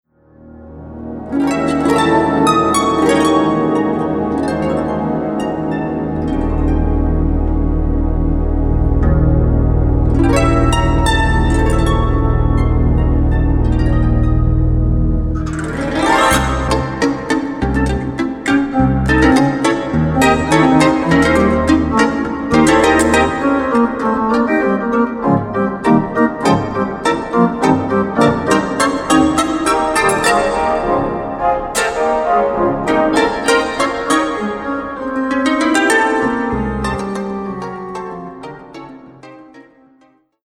free improvisation